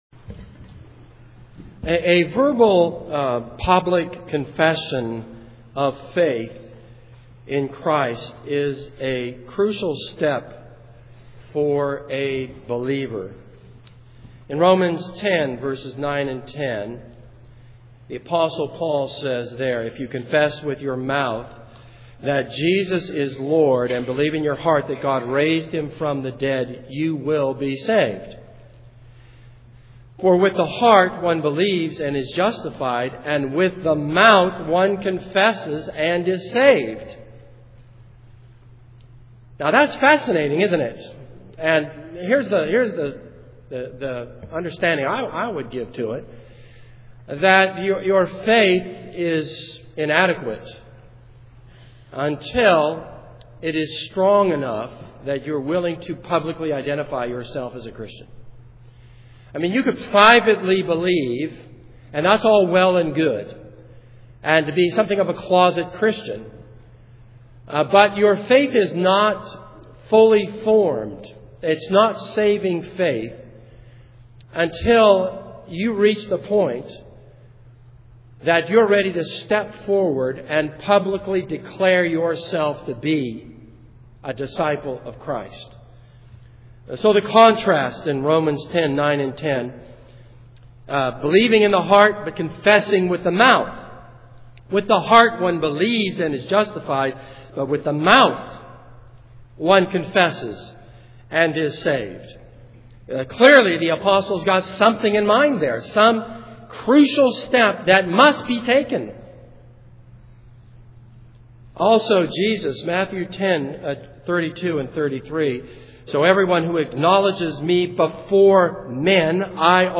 This is a sermon on Esther 7.